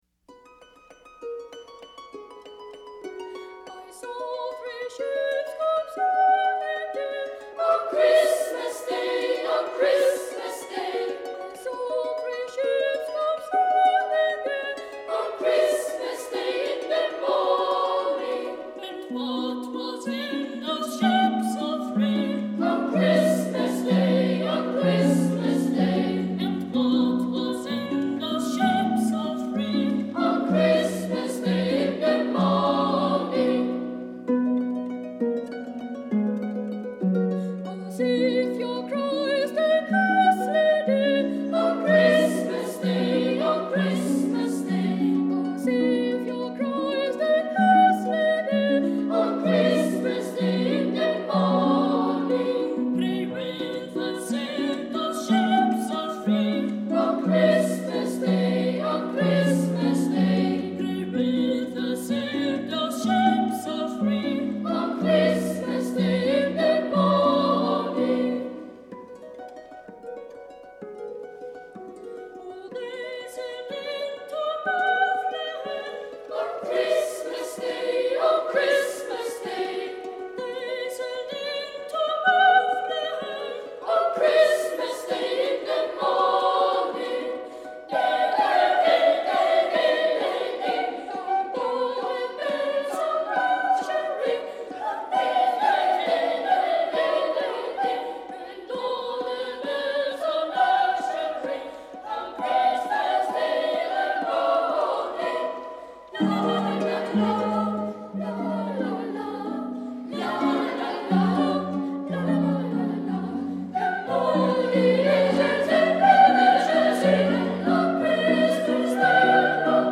This is just such a lovely English Christmas carol. So much joy…